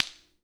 Claps
Clap24.wav